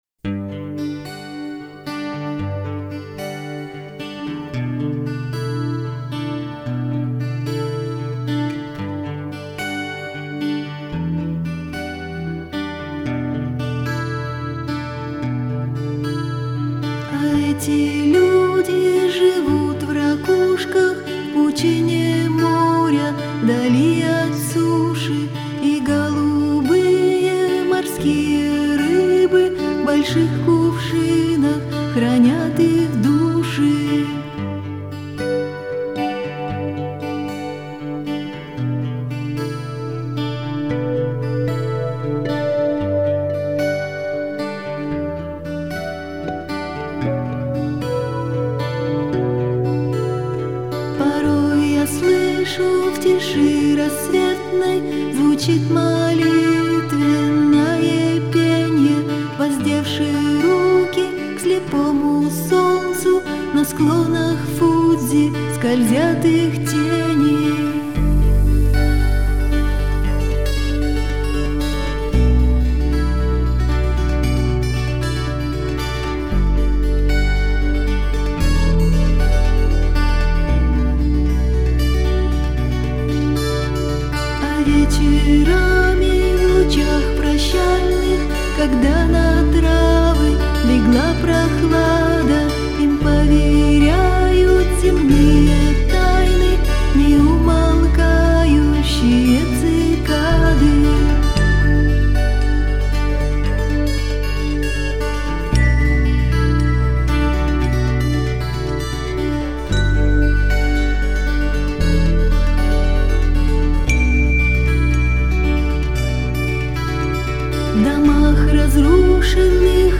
играющая в стиле "Сенти-Ментальный рок".
гитары, клавишные, перкуссия, сэмплы